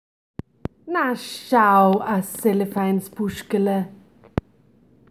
Im Iseltal ein Puschgele.
Und so spricht man das Puschgele aus: